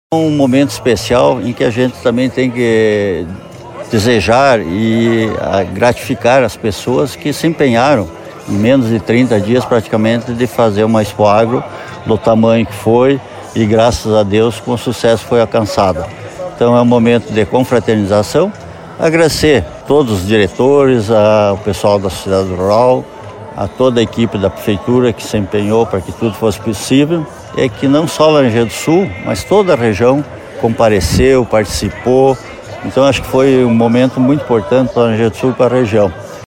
em entrevista à Educadora destacou a grandeza da Expoagro deste ano e parabenizou os organizadores da Festa.